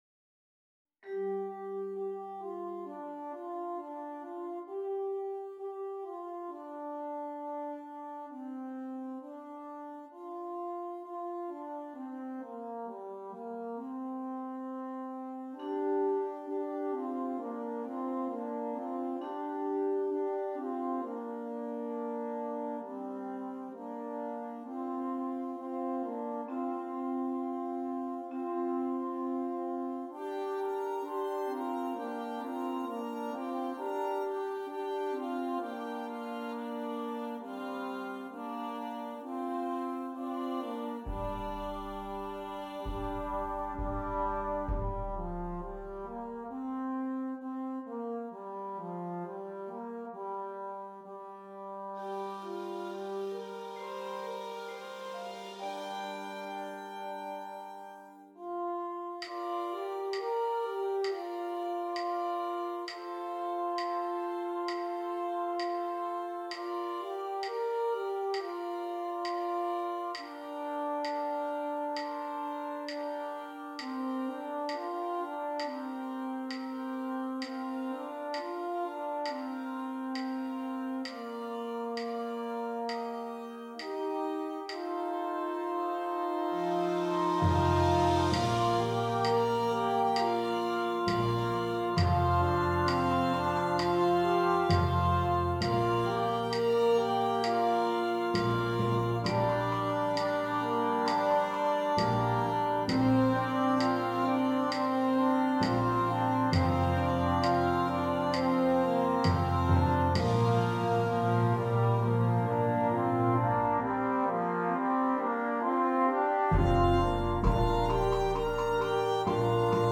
6 Trumpets and Percussion